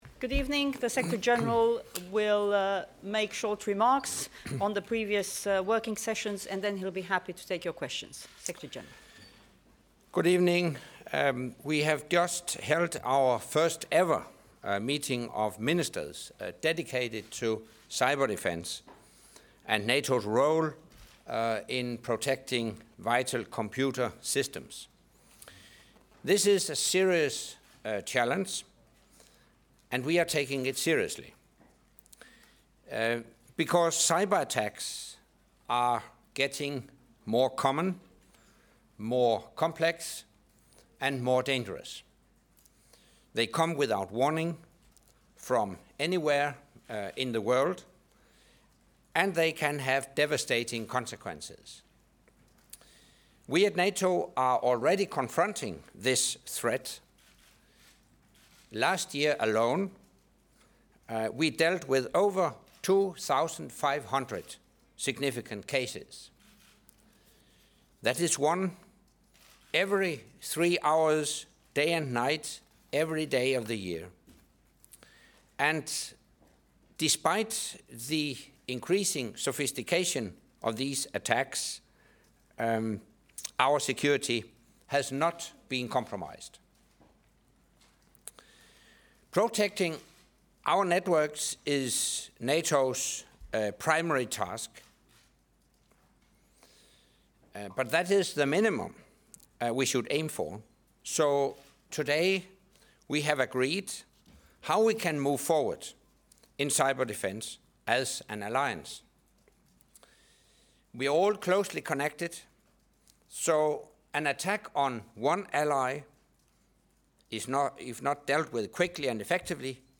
Press conference by NATO Secretary General Anders Fogh Rasmussen following the NATO Defence Ministers meeting